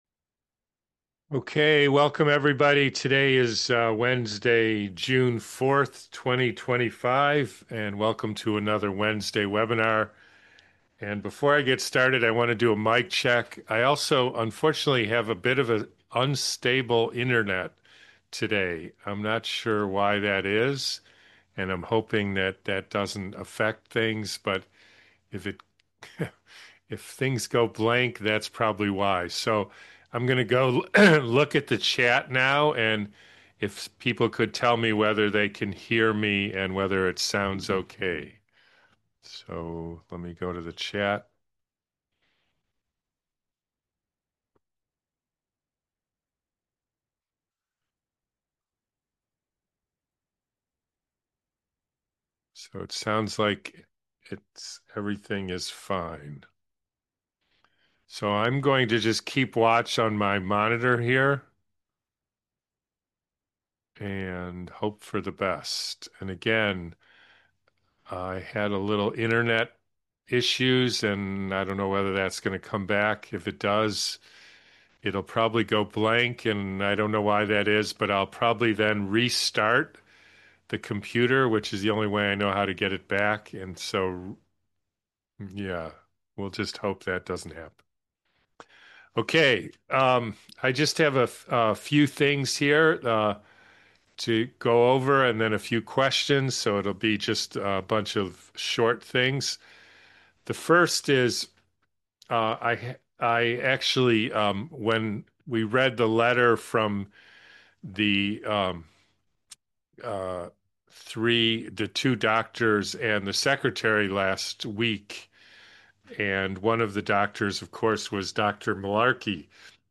webinar-from-june-4th-2025.mp3